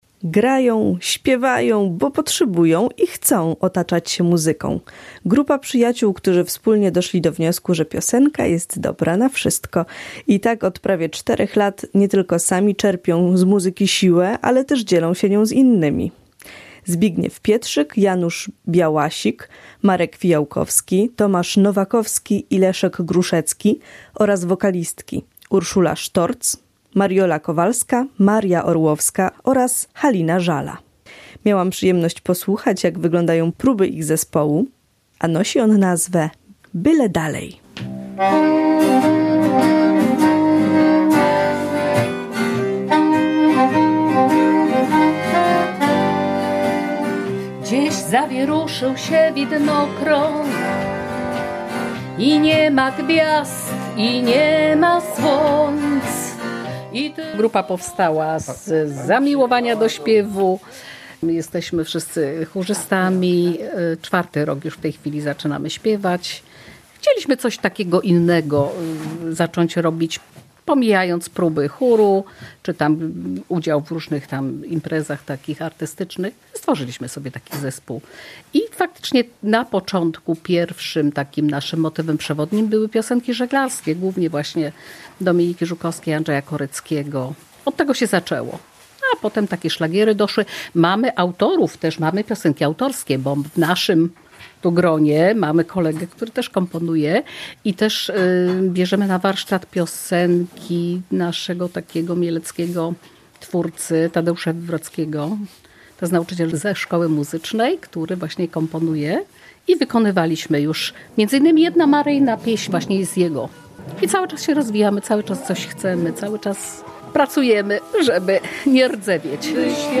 „Byle dalej” to zespół seniorów, którzy z muzyką są związani od wielu lat – zarówno zawodowo, jak i z pasji.